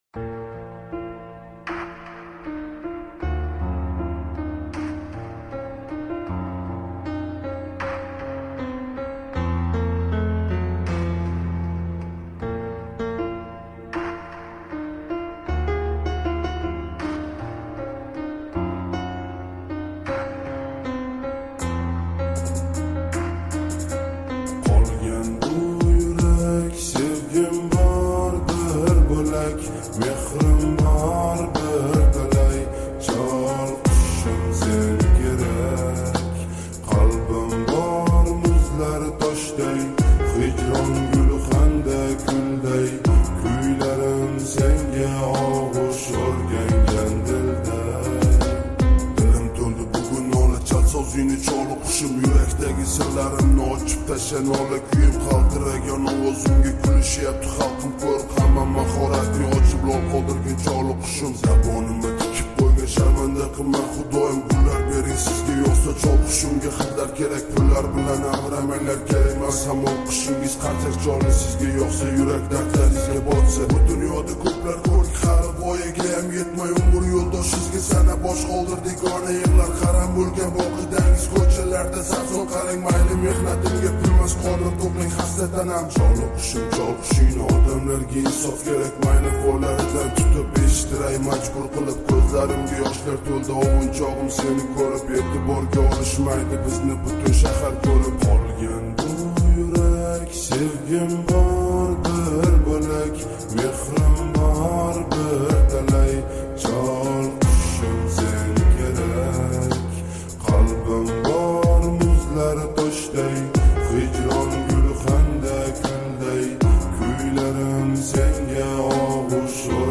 slowed + reverb